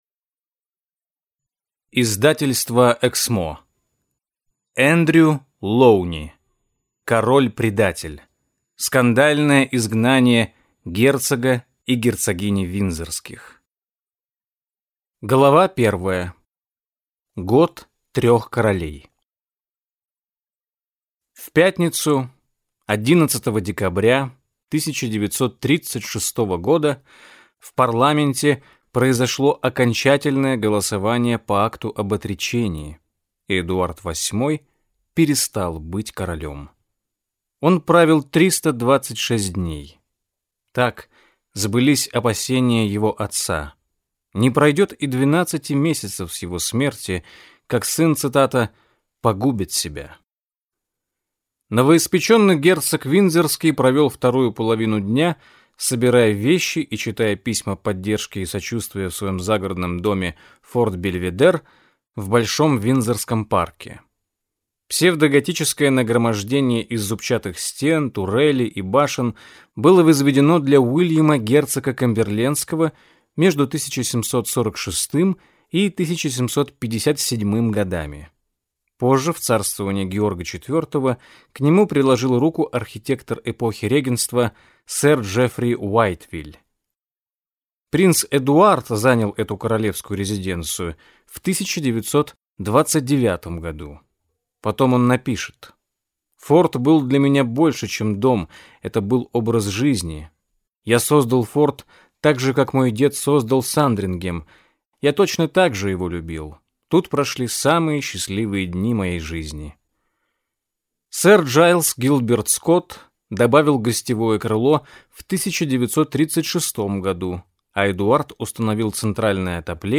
Аудиокнига Король-предатель. Скандальное изгнание герцога и герцогини Виндзорских | Библиотека аудиокниг